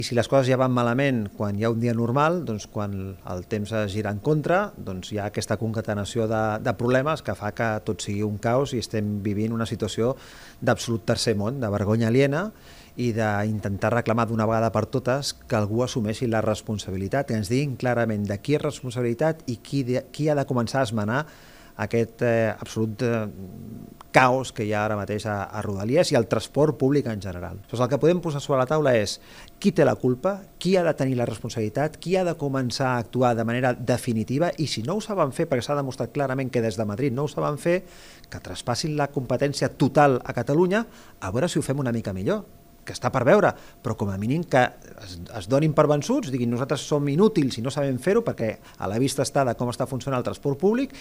El president del Consell, Rafa Navarro, ha qualificat la gestió estatal de “vergonya aliena” i ha assegurat que el servei actual és propi del “tercer món”. Són declaracions a tvmataro: